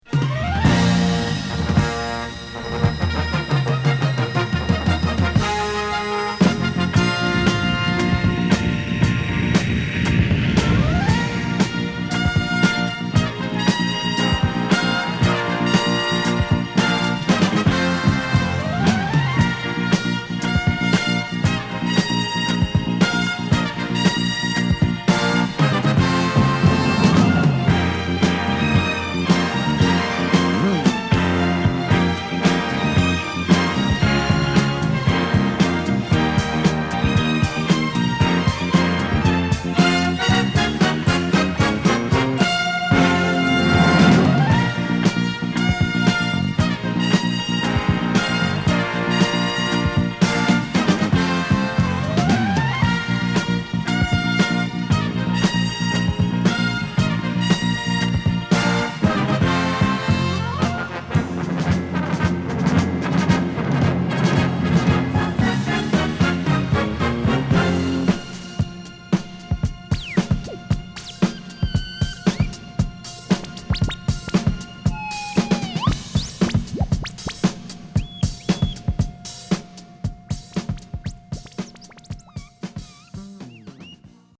7inch